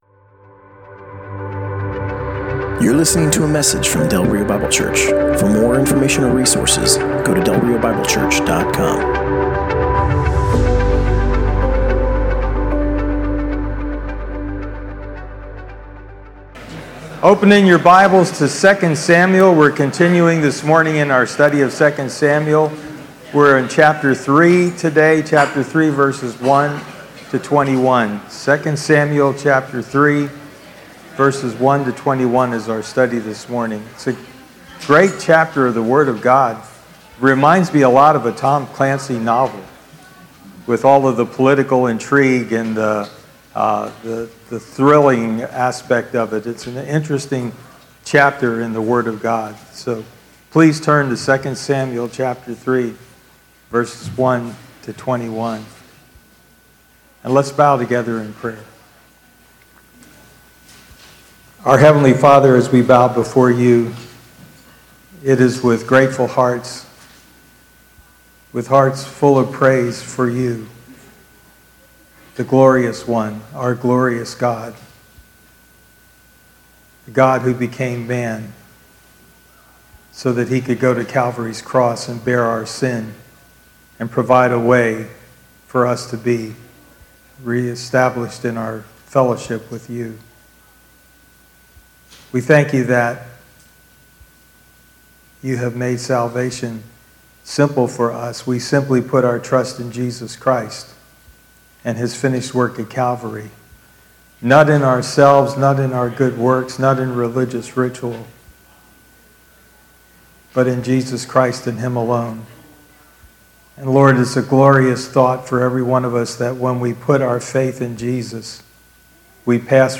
Passage: 2 Samuel 3: 1-21 Service Type: Sunday Morning